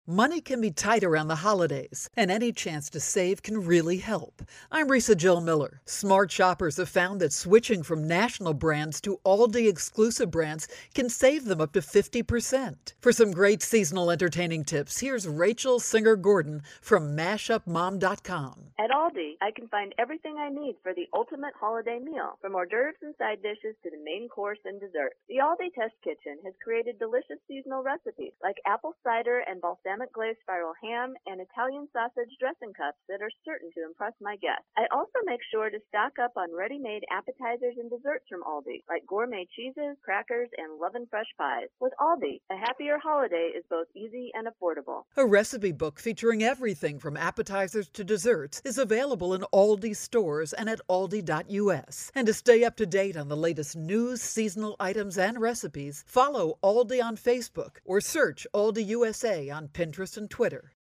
December 4, 2013Posted in: Audio News Release